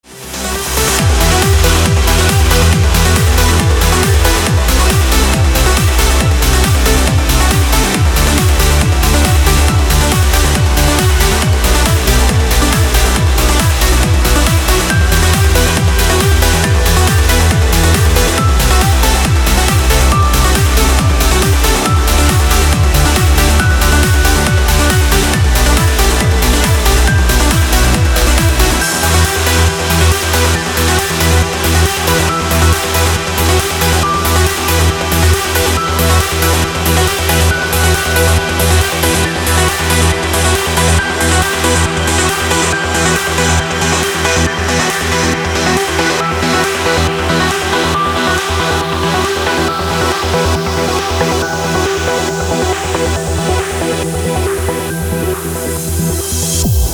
без слов
club
качает
динамичная музыка